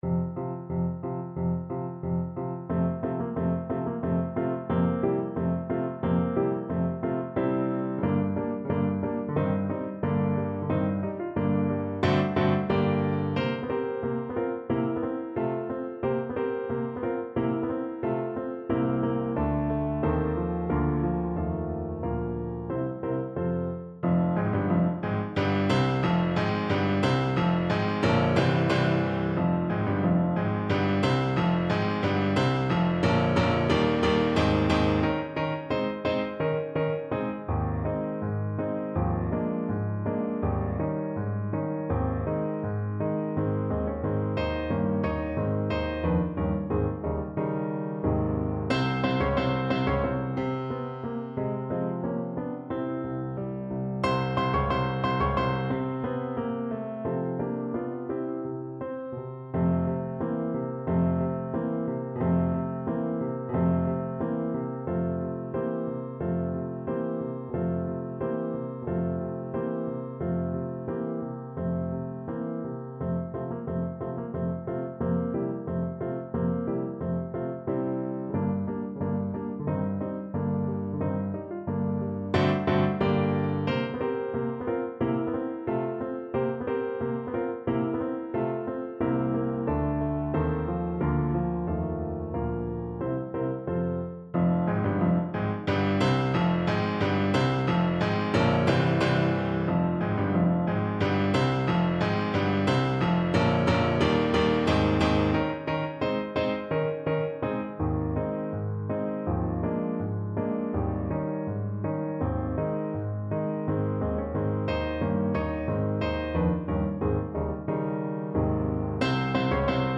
2/4 (View more 2/4 Music)
~ = 100 Allegretto moderato =90
Classical (View more Classical Trumpet Music)